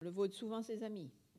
Localisation Bois-de-Céné
Catégorie Locution